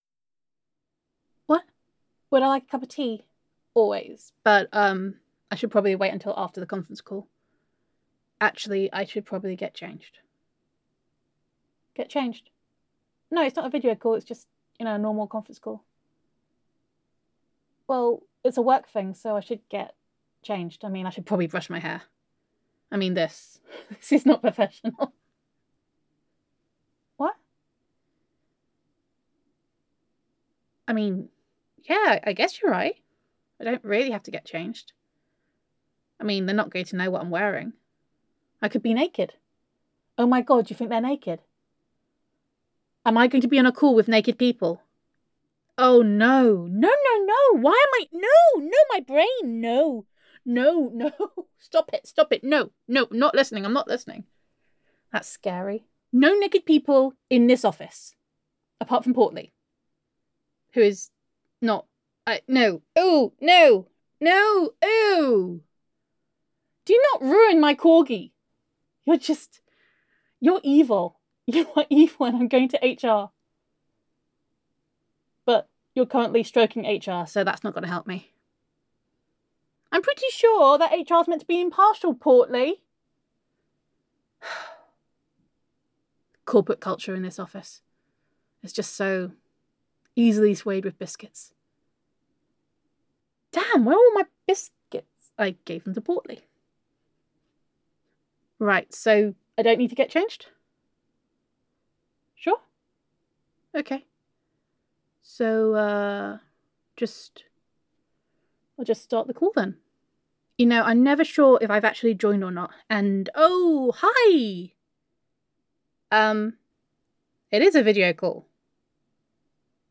[F4A] Day One - The Conference Call [Girlfriend Roleplay][Self Quarantine][Domestic Bliss][Gender Neutral][Self-Quarantine With Honey]